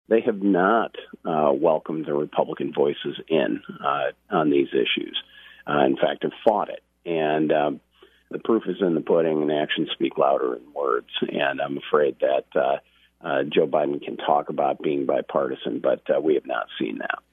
“They (majority Democrats) have not welcomed the Republican voices in on these issues, and in fact have fought it,” he said on “WHTC Morning News” during his weekly Thursday interview.